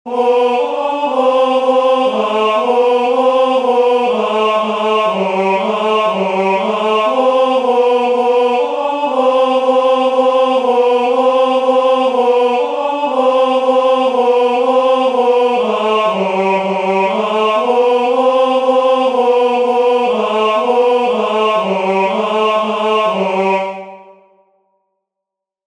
The first chant of Rauner 003203 folio 010v "Gloria patri," from the third nocturn of Matins, Common of Apostles